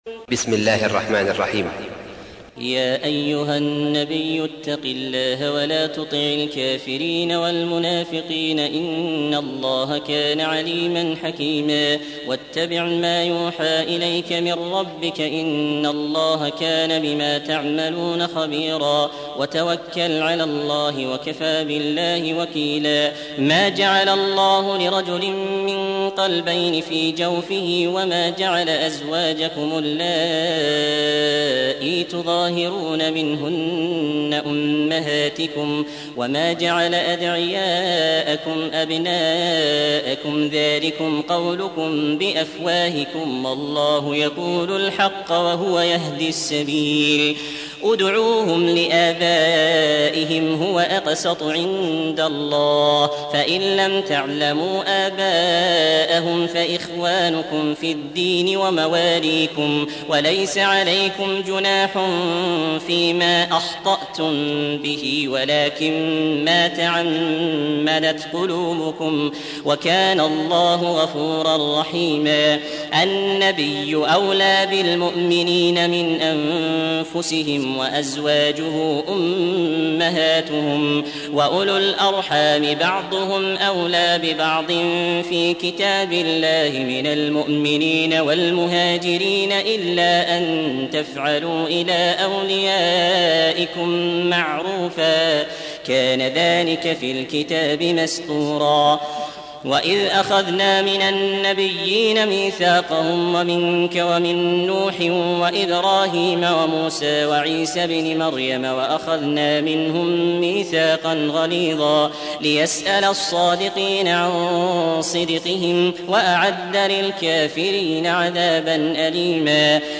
33. سورة الأحزاب / القارئ